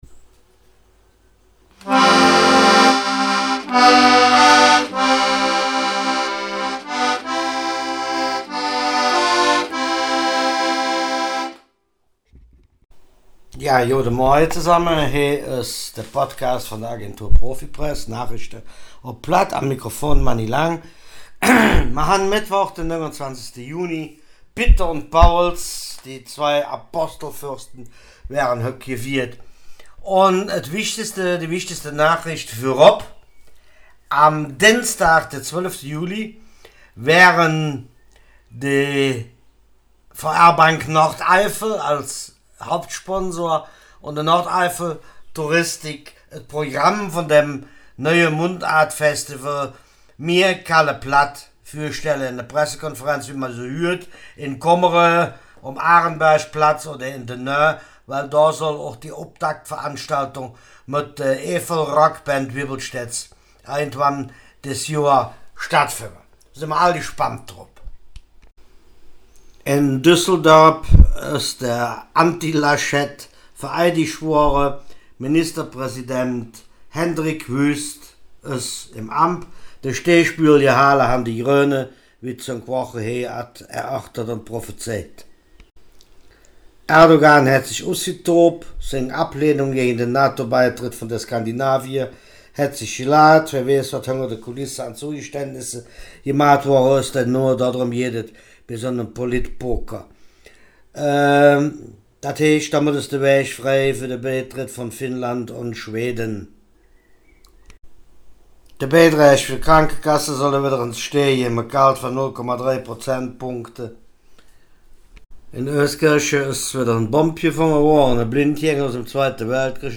Nachrichten vom 29. Juni
Podcast op Platt Nachrichten vom 29.